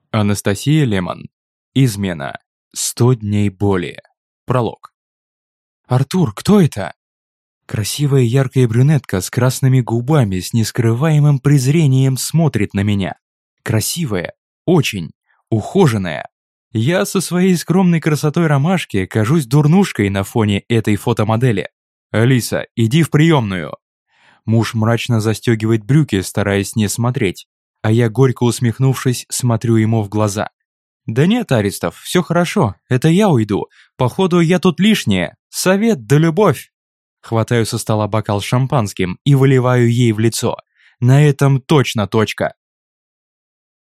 Аудиокнига «Диплом для Золушки».